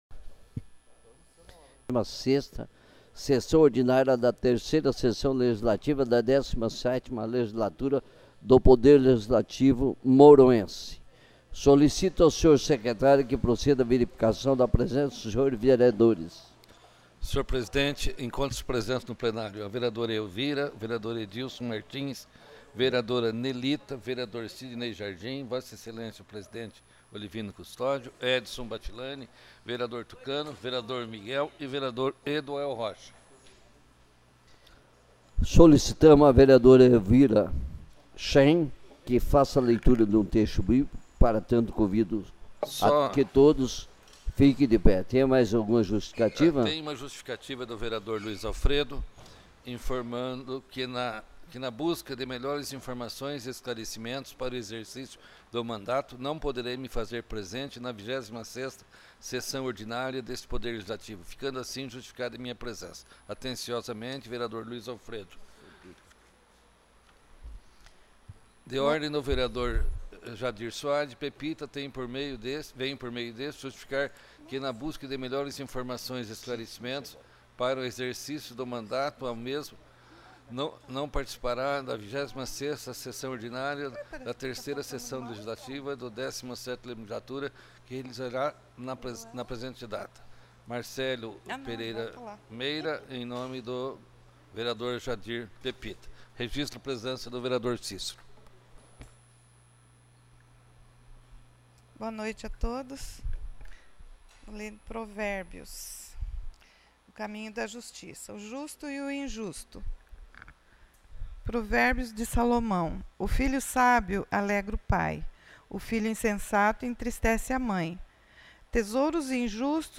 26ª Sessão Ordinária